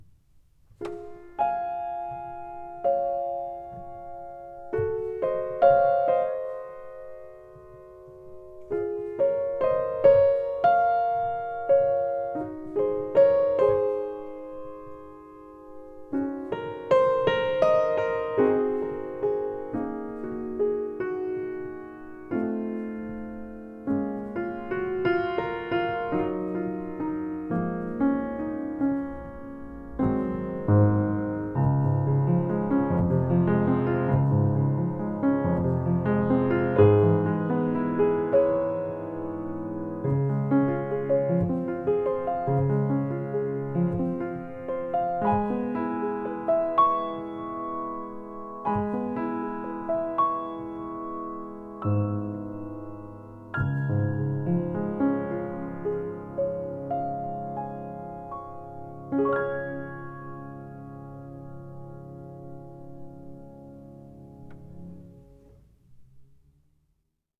An excerpt of Claude Debussy's classic piano piece "Clair de Lune
sounds-of-mars-clair-de-lune-earth.wav